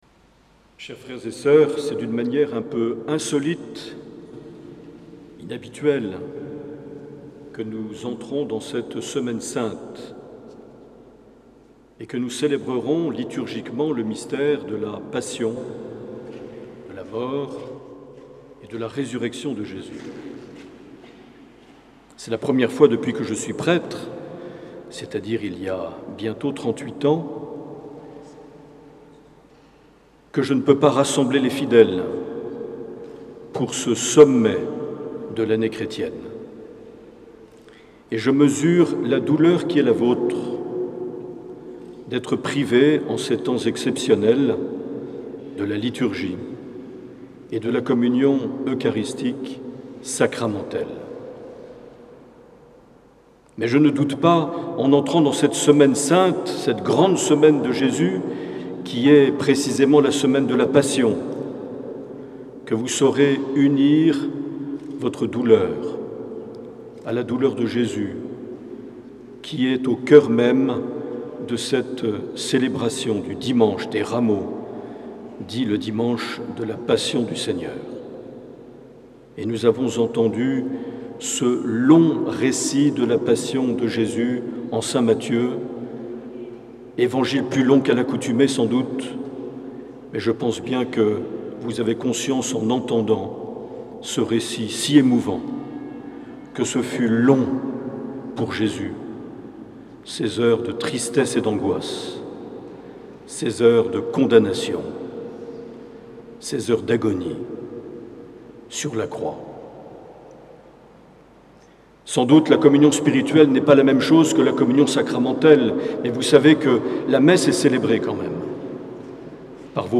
04 avril 2020 - Rameaux et Passion du Seigneur - Cathédrale de Bayonne
Accueil \ Emissions \ Vie de l’Eglise \ Evêque \ Les Homélies \ 04 avril 2020 - Rameaux et Passion du Seigneur - Cathédrale de (...)